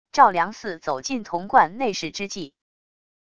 生成语音 下载WAV